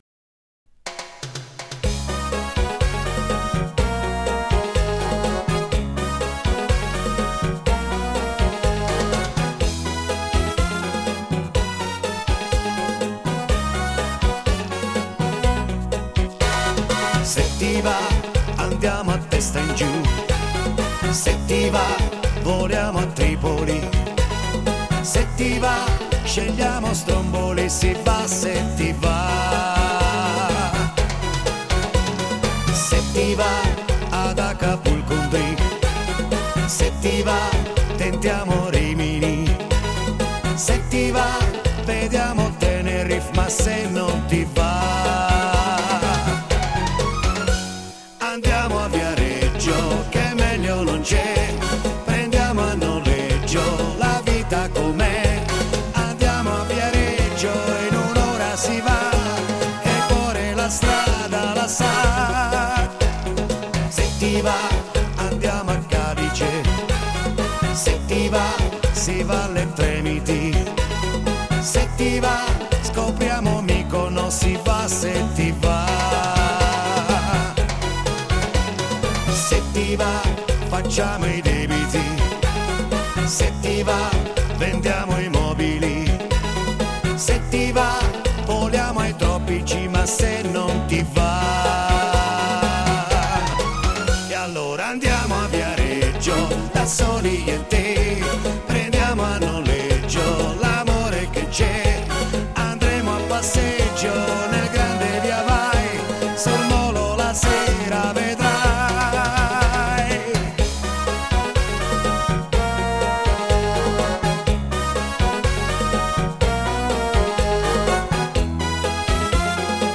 Genere: Cha cha cha